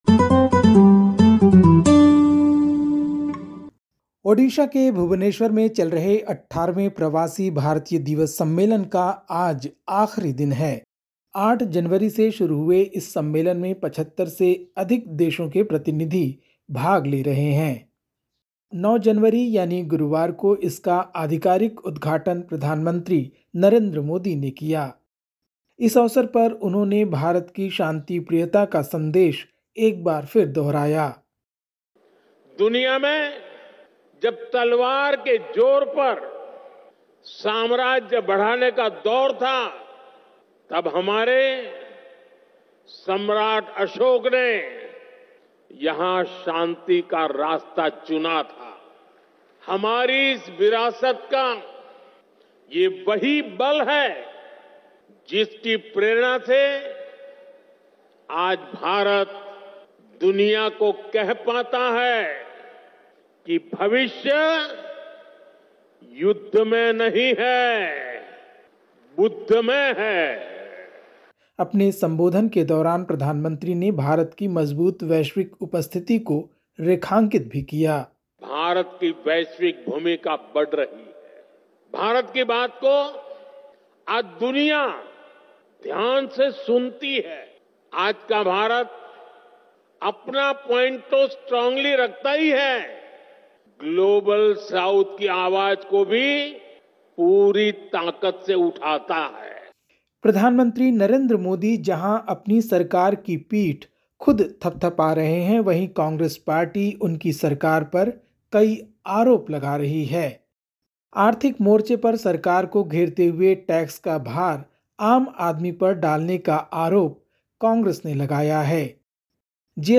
Listen to the latest SBS Hindi news from India. 10/01/2025